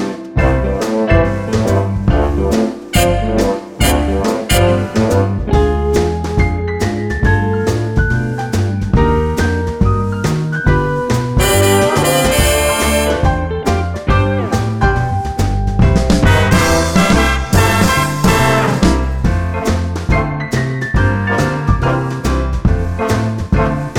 Crooners